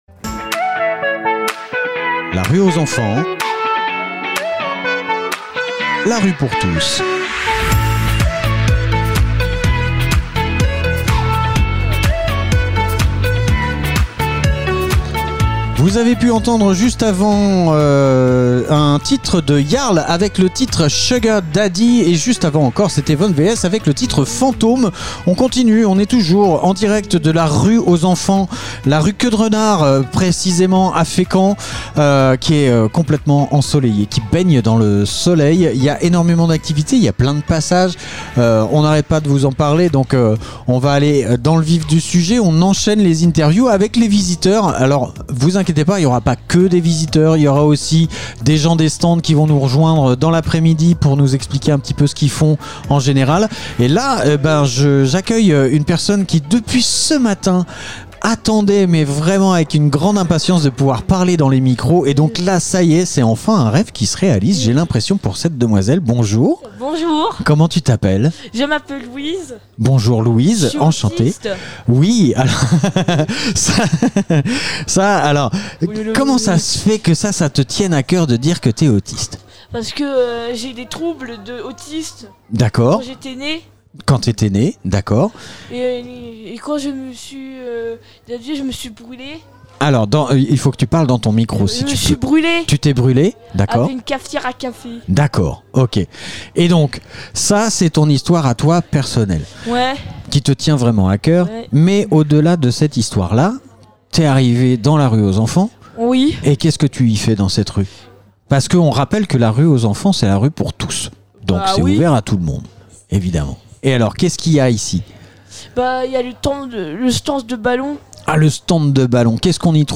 La rue aux enfants Interview fécamp associations association rue aux enfants